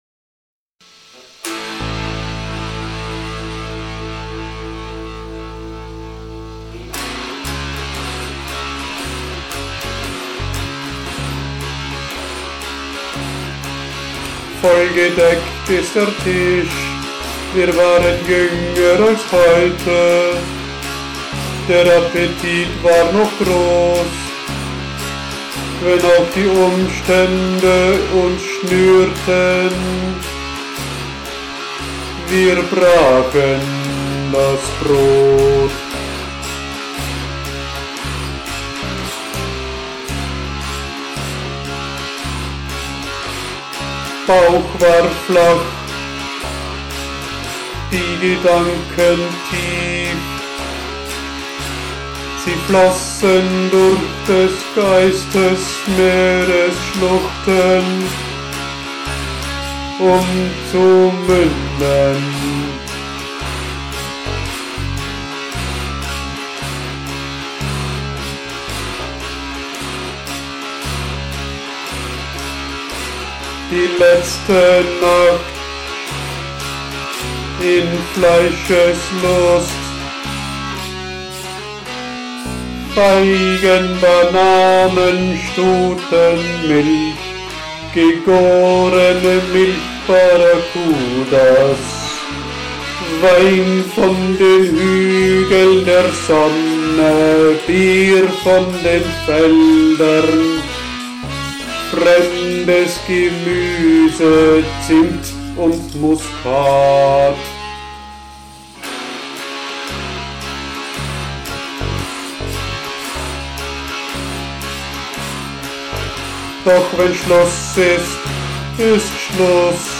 Literaturlieder von brüchiger Schönheit, verwegene Tongänge